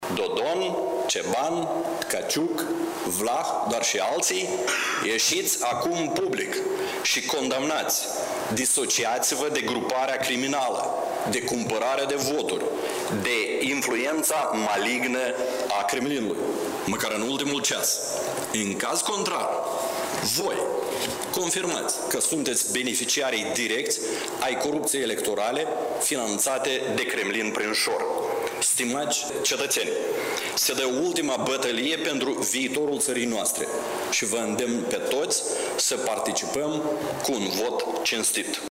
„Presiunea devine tot mai mare, iar probele în ceea ce privește acțiunile subversive ale Rusiei sunt tot mai numeroase”, a spus premierul Dorin Recean într-o conferință de presă.